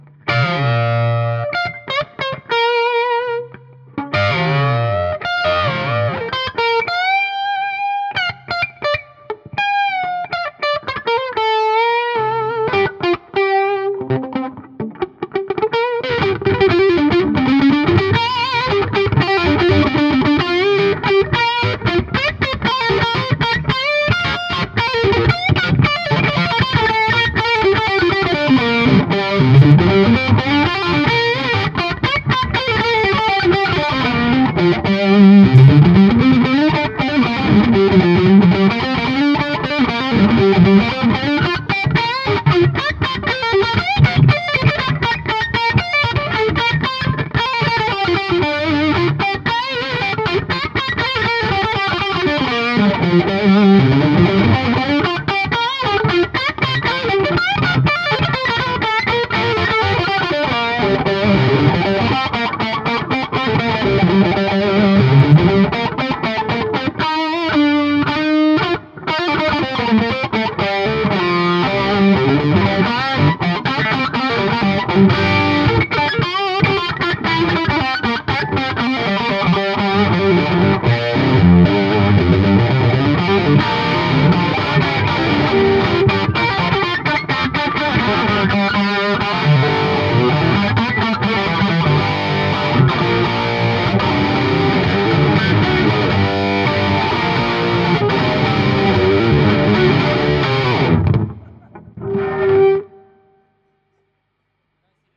Par contre à l'enregistrement le diezel devient plus crémeux, plus chaud et l'xtc plus baveux, quant à la dynamique elle n'est absolument pas restituée...
Bon vite fait avant d'aller bosser voici 4 samples Diezel et 1 Bogner.
C'est pas terrible: y a trop d'effet, c'est pas accordé, y a du plantage dans tous les sens, et les niveaux d'enregistrement sont trop forts ce qui provoque des pok (surtout sur les 2 derniers samples )...